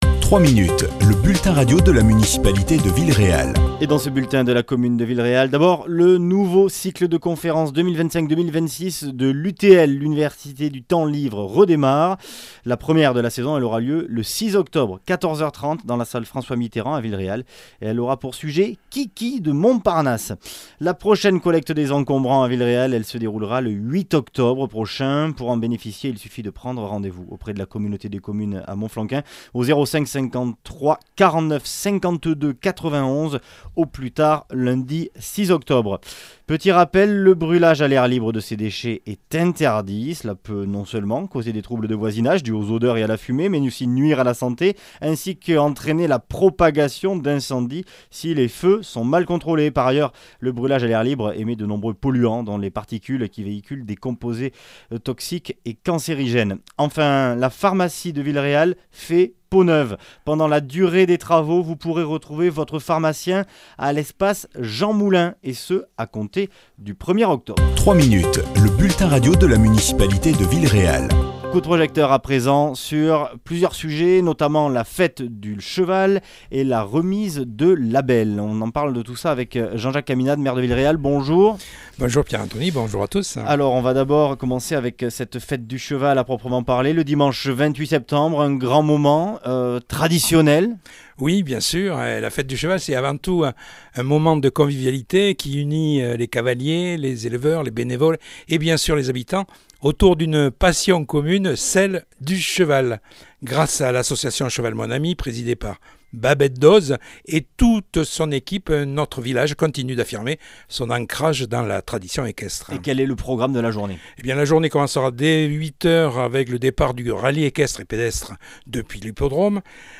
Double rendez-vous, ce dimanche 28 septembre, avec la Fête du Cheval mais aussi la remise du label "Village d'accueil des véhicules d'époque". Les explications du maire, Jean-Jacques Caminade.